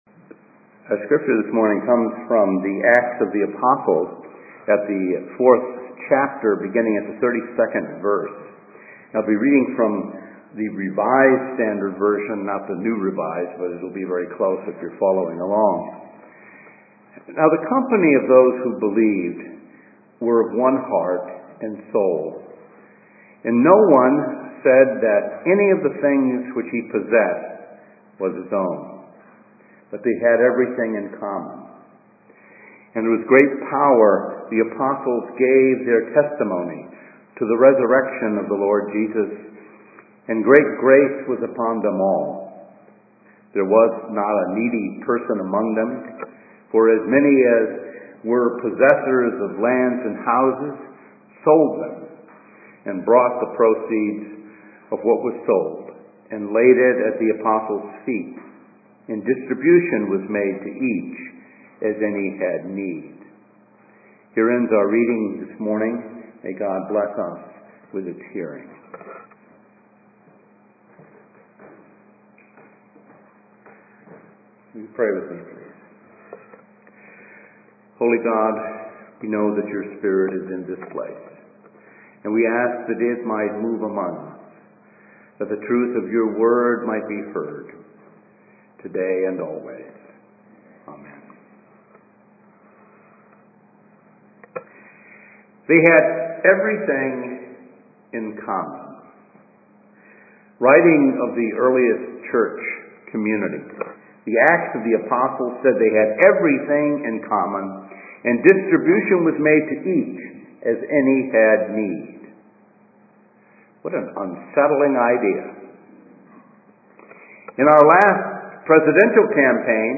A message from the series "Lectionary."